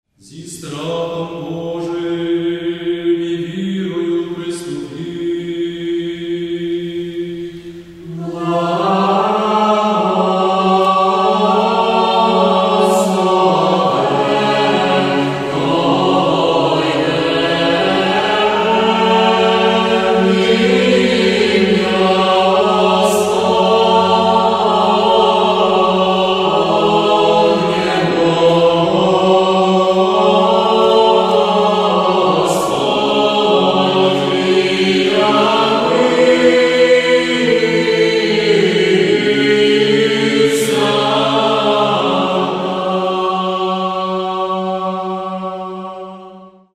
Церковна